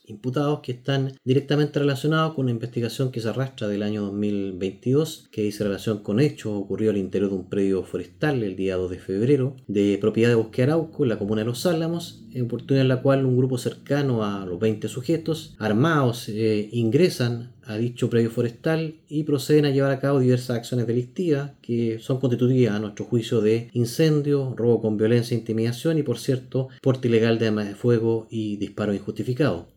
El Fiscal Adjunto Jefe de Cañete, Danilo Ramos, se refirió al día de los hechos e indicó que a los imputados se les acusa de incendio, robo con violencia e intimidación, porte de arma de fuego y disparos injustificados.